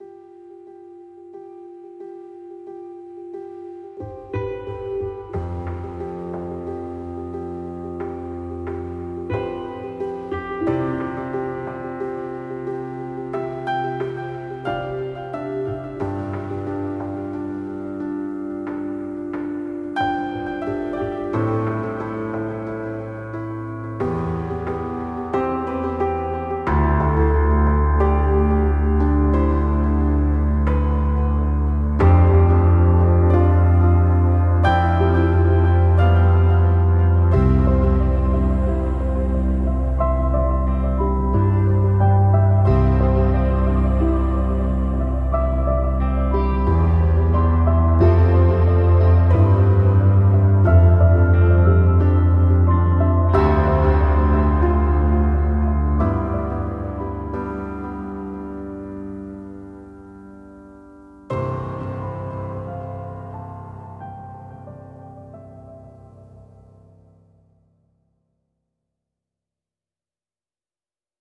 键盘钢琴 Sample Logic Key Fury KONTAKT-音频fun
他不止是一架钢琴，还是具有多种特殊效果的创意音源。
- 它使用了多种键盘乐器的采样，包括大钢琴、电钢琴、合成器、有机键盘等，制作出了富有戏剧性和情感的电影风格的音色。
- 它具有热插拔效果链技术，可以随时更换和调整音色的效果，包括滤波、失真、混响、延迟等。